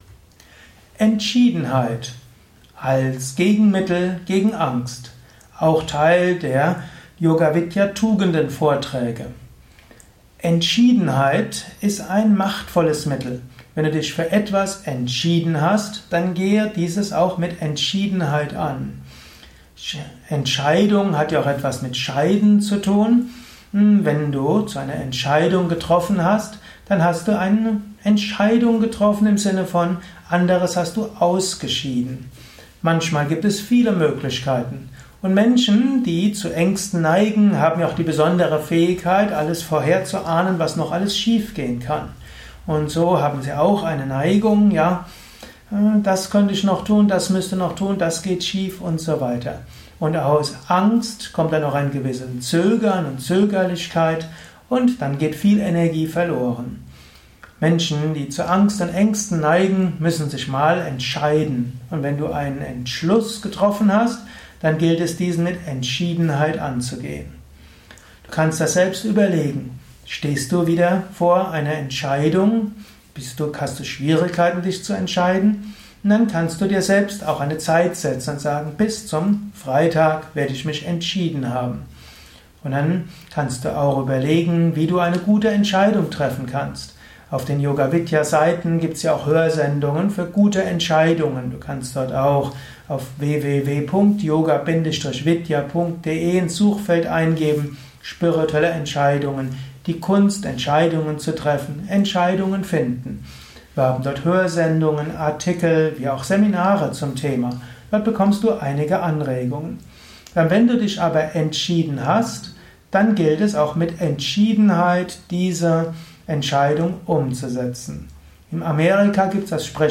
Abhandlung mit dem Inhalt Entschiedenheit gegen Angst. Erfahre einiges zum Thema Entschiedenheit in diesem Audiovortrag.